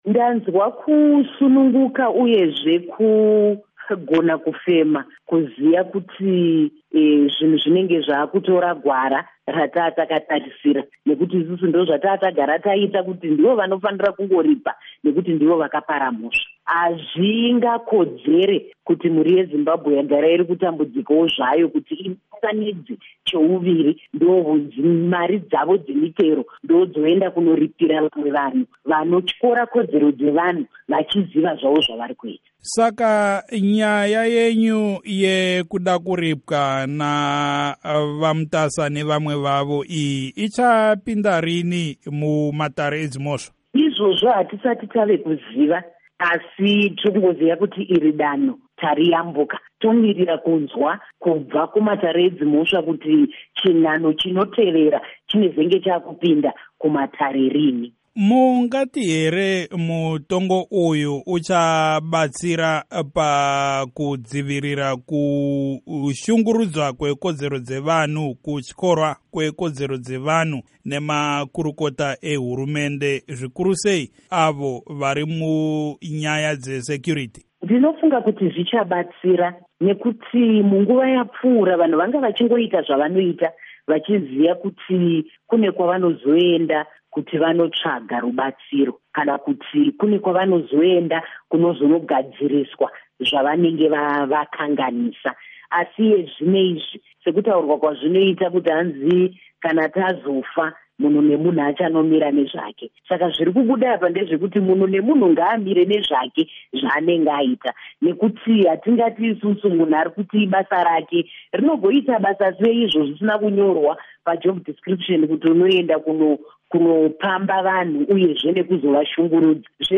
Hurukuro naMuzvare Jestina Mukoko